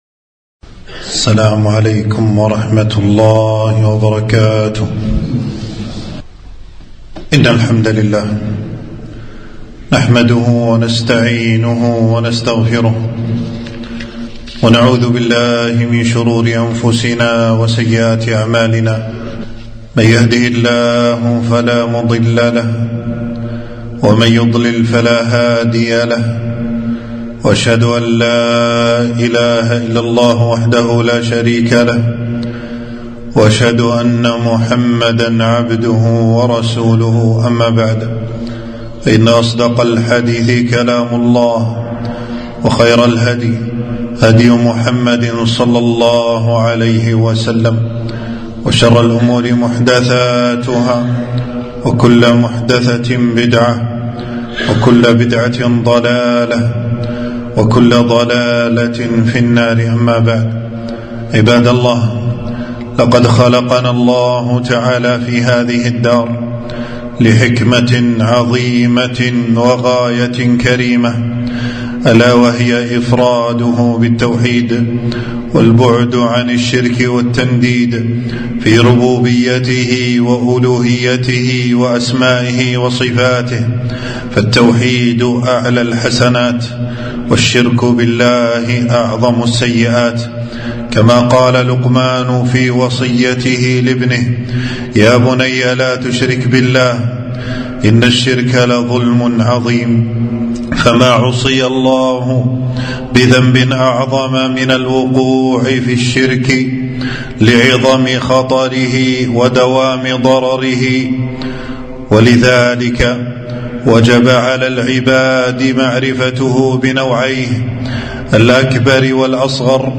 خطبة - الشرك الأصغر خطورته وصور علاجه